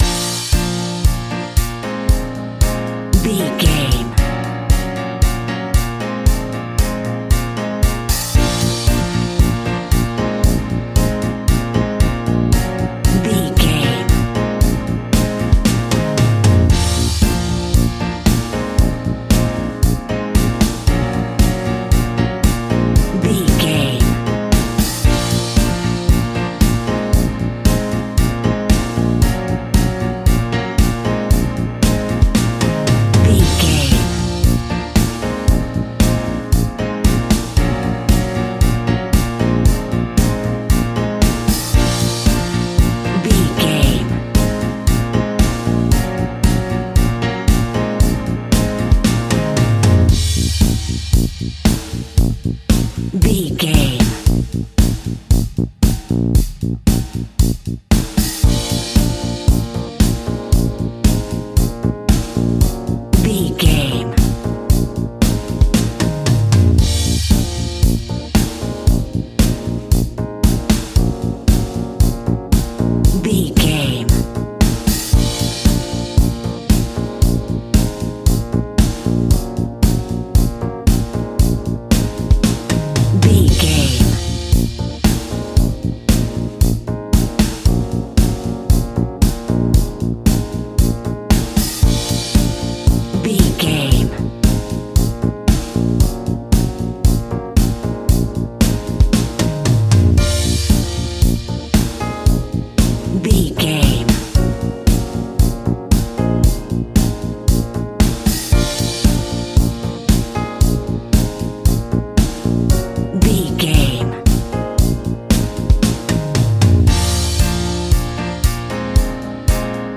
Mixolydian
B♭
pop rock
indie pop
fun
energetic
uplifting
acoustic guitars
drums
bass guitar
electric guitar
piano
organ